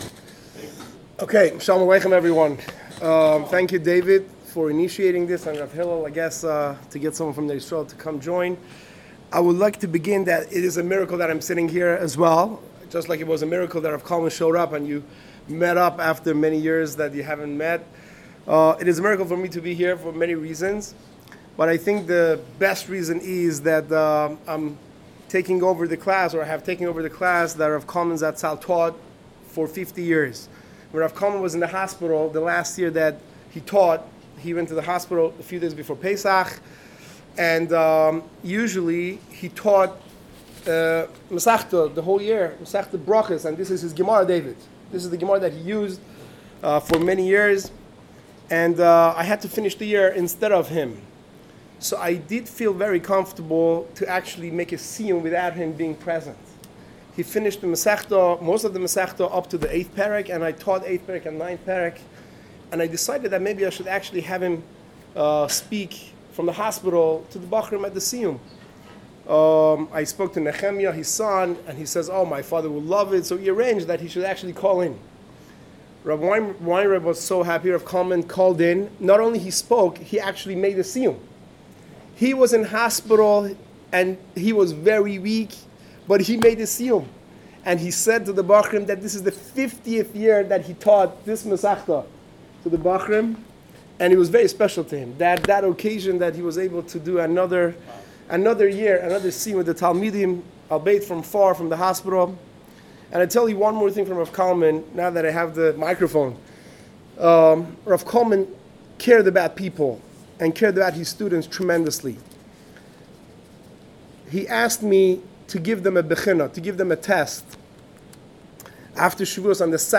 Last week the Yeshiva held a Lunch and Learn in Downtown Baltimore in preparation for the Yomim Noraim.
lunch-and-learn-shiur-1.mp3